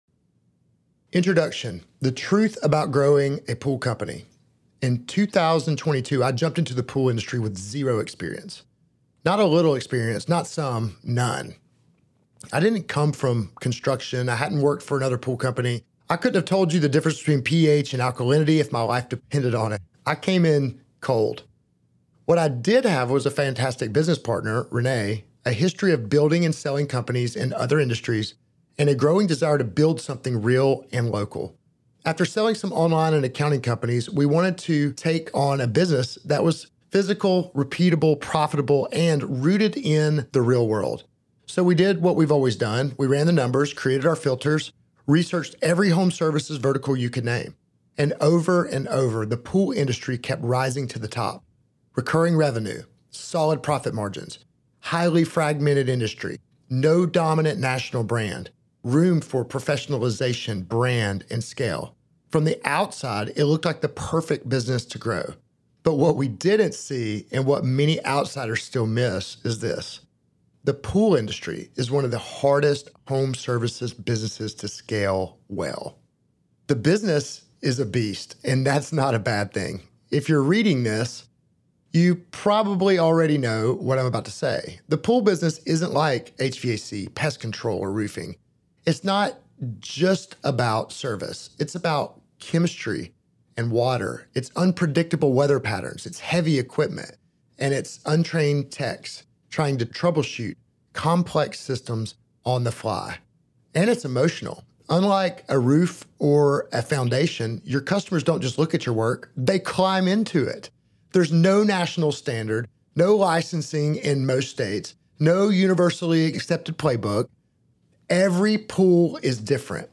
From Skimming to Scaling Audiobook.mp3